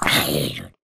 zombiehurt2.ogg